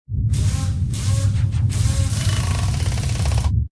repair4.ogg